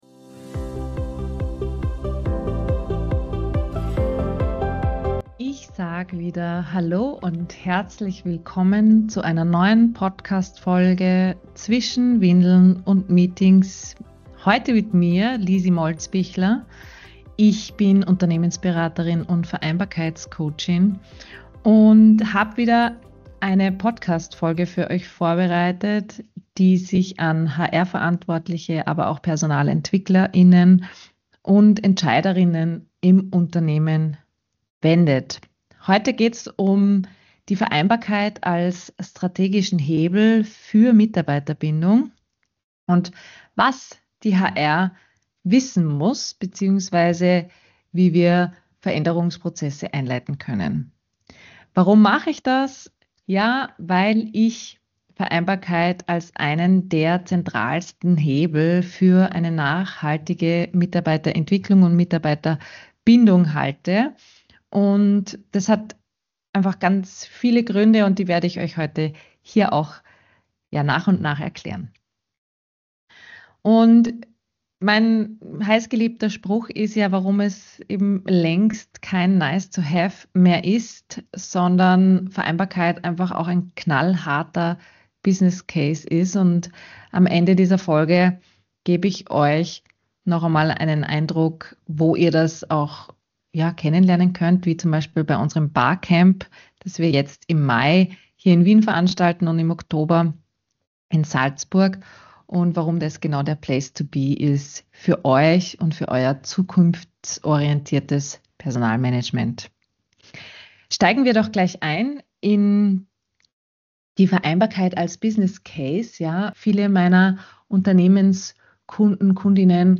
In dieser Solo-Folge von Zwischen Windeln und Meetings geht es um das, was oft hinter den Kulissen entscheidet, ob Vereinbarkeit wirklich gelingt: die strategischen Hebel in Organisationen.